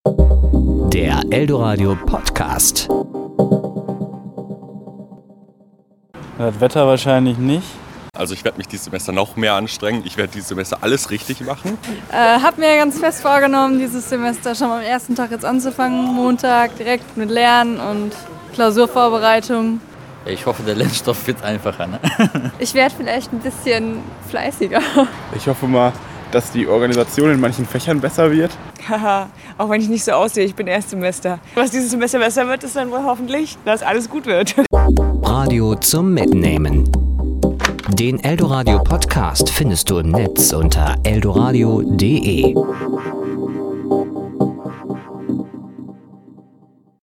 Serie: Wort